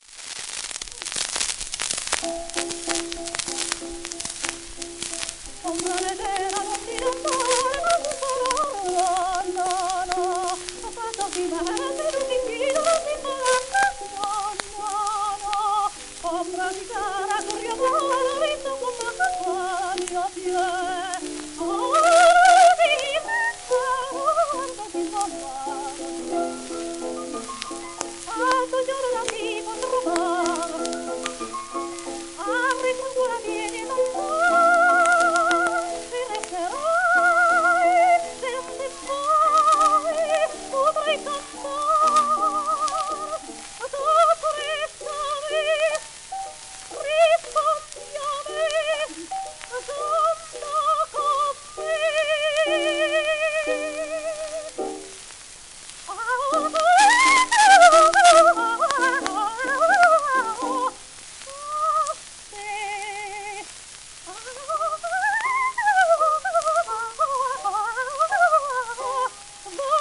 w/ピアノ
盤質A- *センターホール荒れ,導入他一部溝荒,小キズ
1905年頃録音